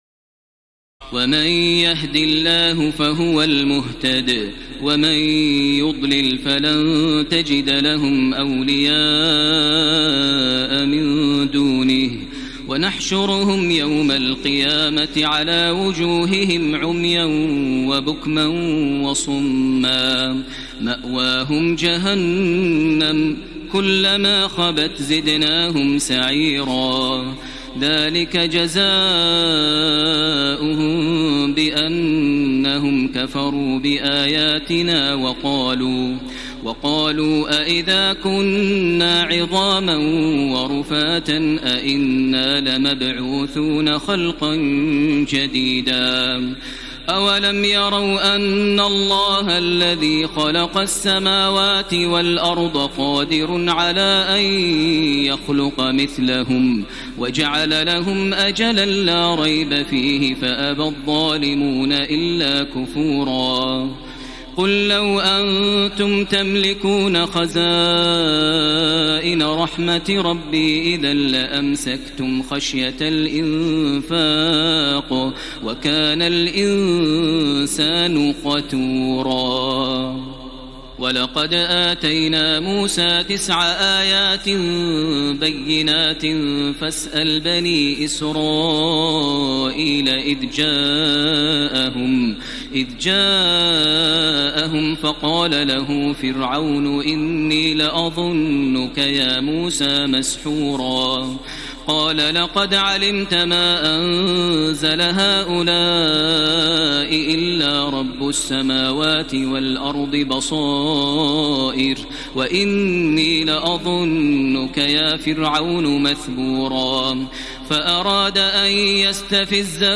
تراويح الليلة الرابعة عشر رمضان 1433هـ من سورتي الإسراء (97-111) والكهف (1-82) Taraweeh 14 st night Ramadan 1433H from Surah Al-Israa and Al-Kahf > تراويح الحرم المكي عام 1433 🕋 > التراويح - تلاوات الحرمين